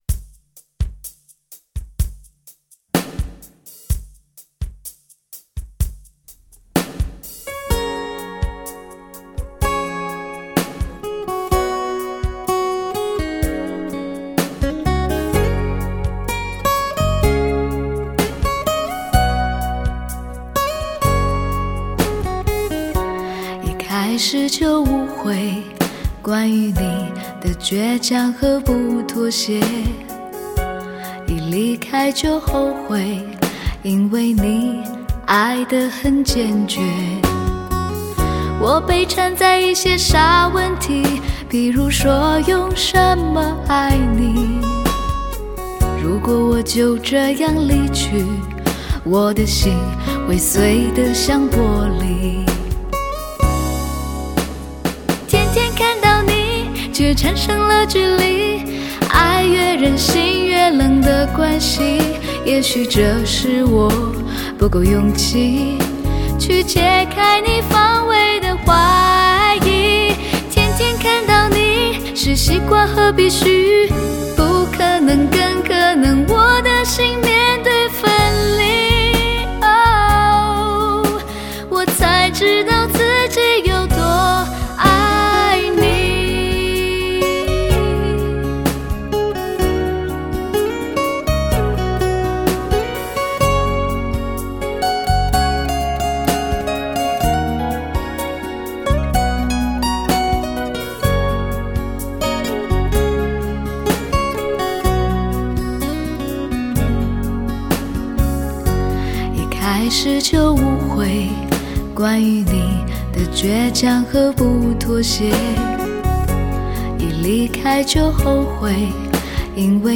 经A2HD3制版的大碟，更准确保留原录音的优点，声音更自然、更平衡，质感、细节、动态、瞬变大幅提升，模拟味道更充分……